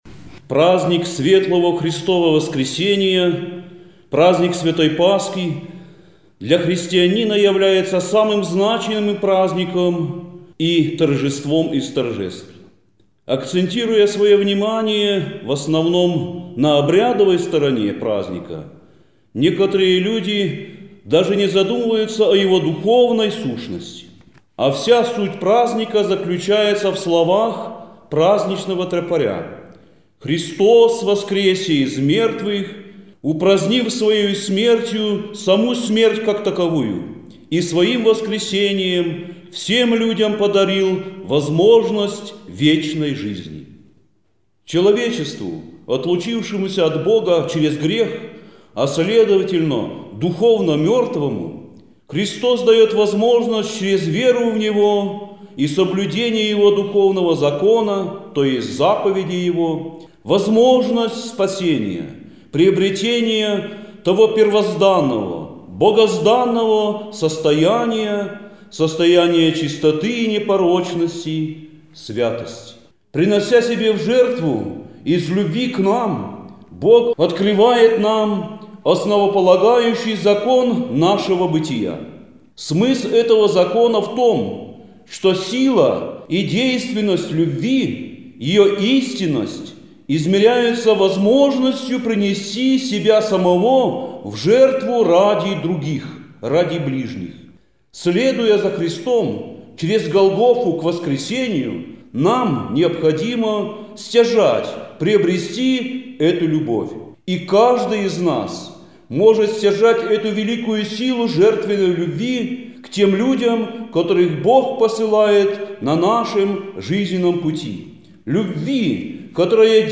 пасхальном обращении